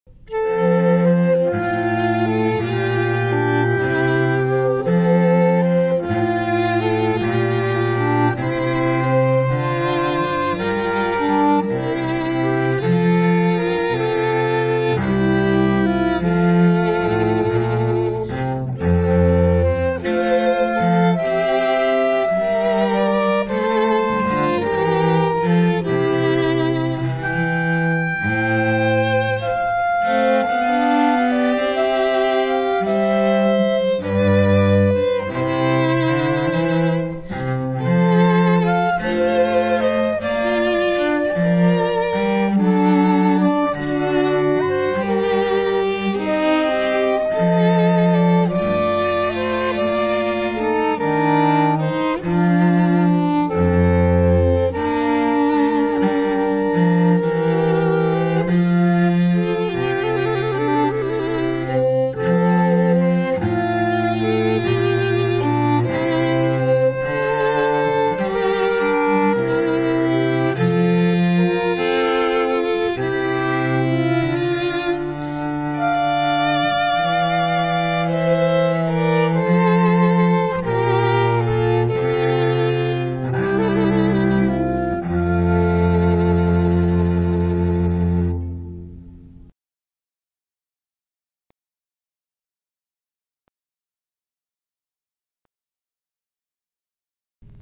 Air - Water Music Handel Flute/String Trio